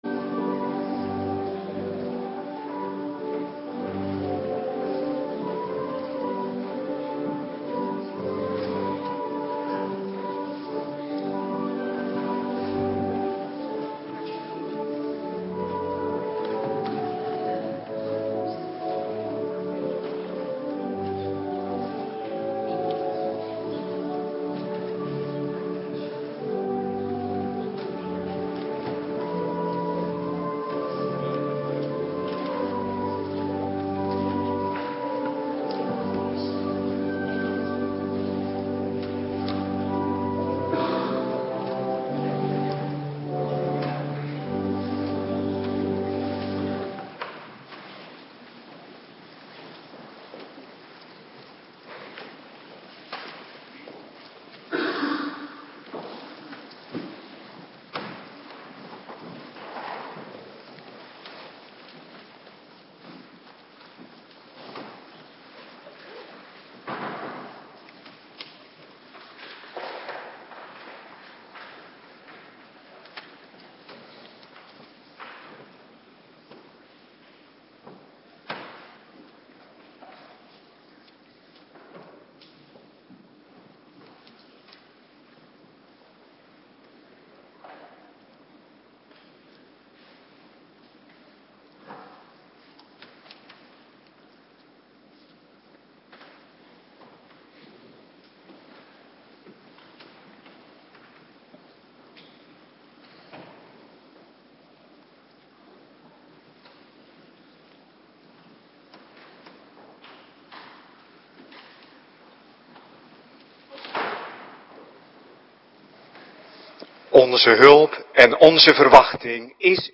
Avonddienst Eerste Kerstdag - Cluster 3
Locatie: Hervormde Gemeente Waarder